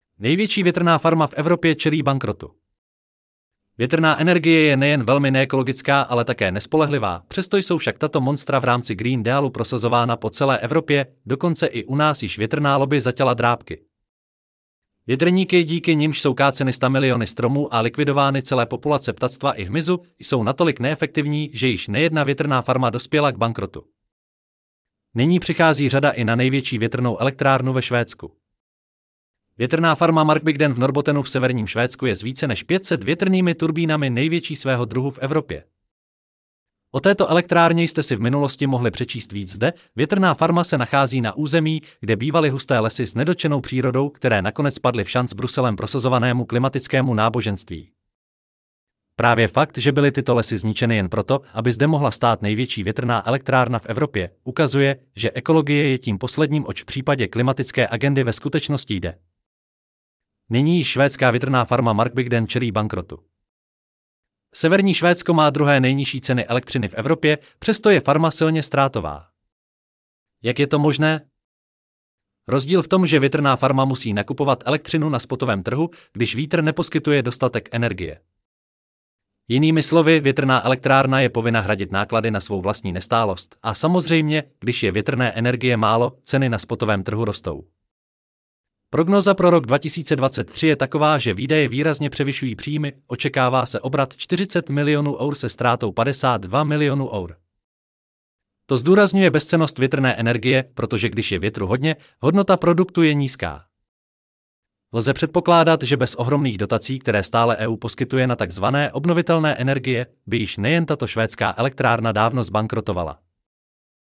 Největší větrná farma v Evropě čelí bankrotu Celý článek si můžete poslechnout v audioverzi zde: NEJVETSI-VETRNA-FARMA-V-EVROPE-CELI-BANKROTU 13.11.2023 Větrná energie je nejen velmi neekologická, ale také nespolehlivá.